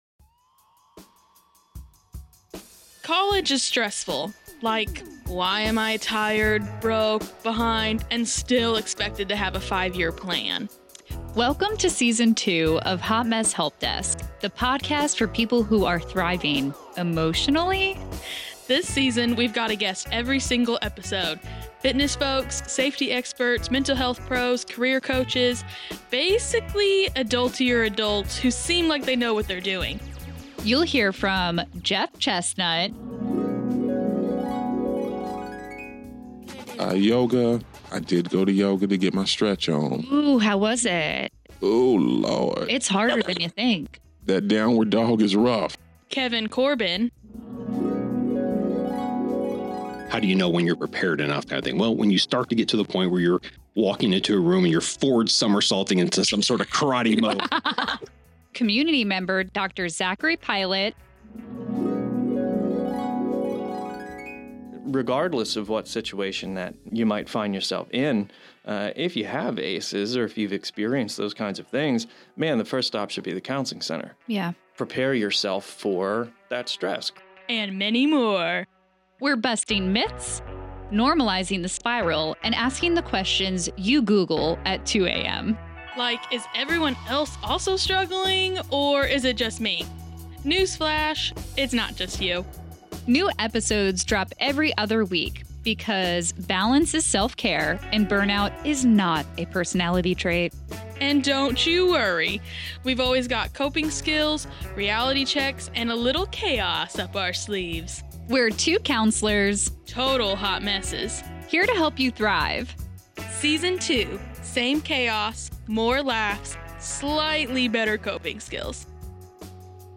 College stress, mental health, fitness, safety, careers, and everything in between—we’ve got something for everybody. With a guest every episode, two counselor hosts break down the chaos, bust myths, and normalize the spiral with humor, honesty, and real coping skills.